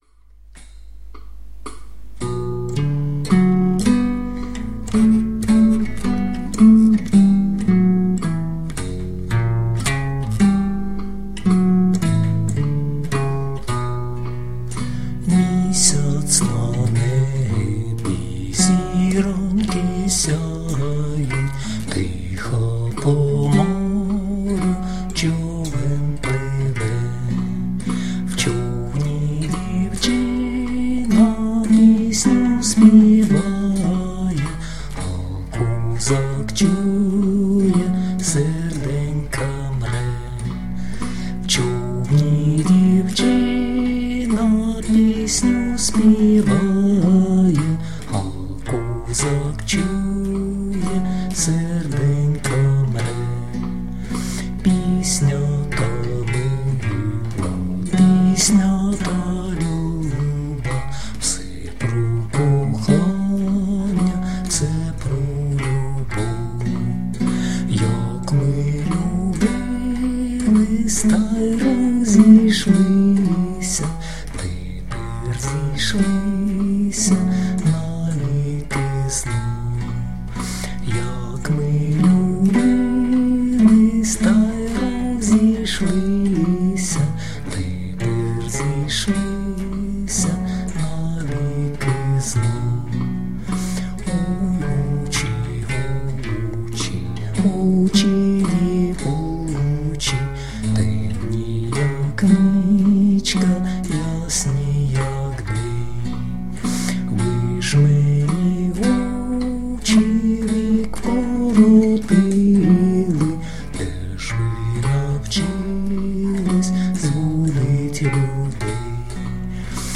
../icons/moydrugr.jpg   Українська народна пiсня